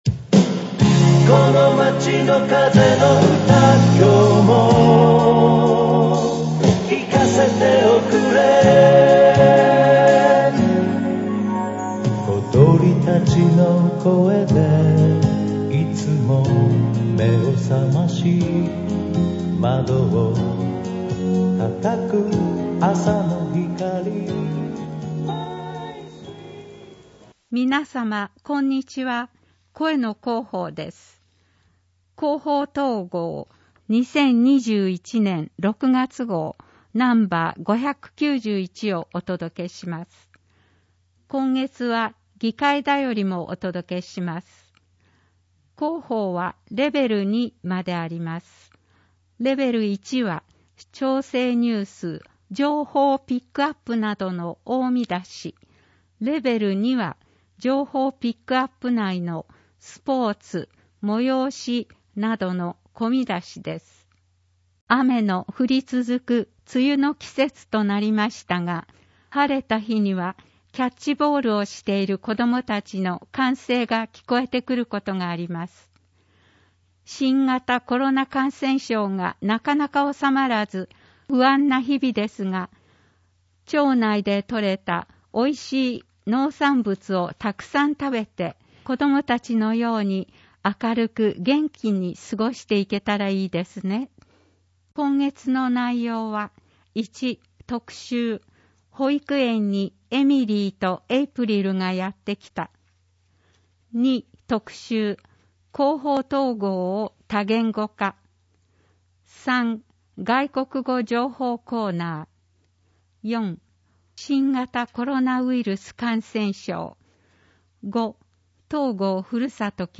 広報とうごう音訳版（2021年6月号）